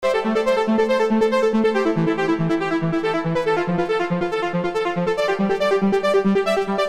These samples are primarily designed for the Dance eJay series of programs i.e. They are all in A-minor and run at 140BPM.
Distortedlead_2 - A distorted trance lead with a hint of strings.
distortedlead_2.mp3